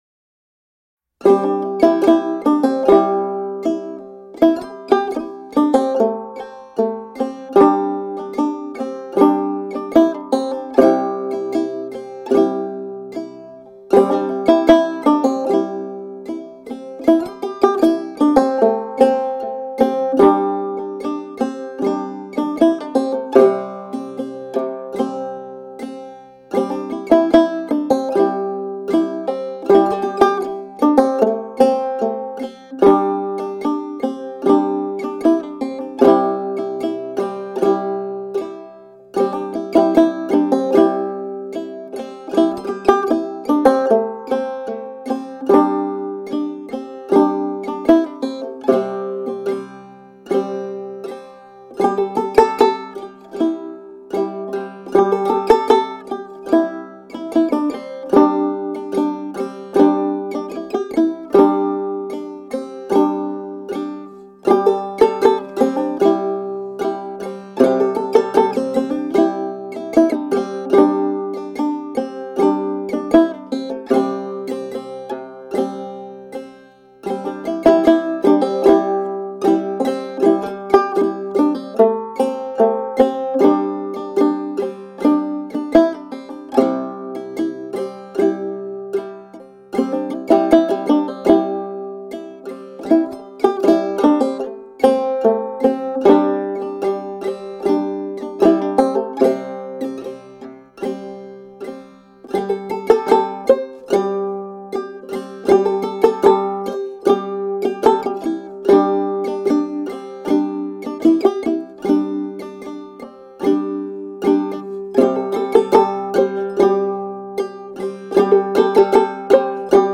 The album was recorded in Shanghai.
Tagged as: World, Instrumental World, Folk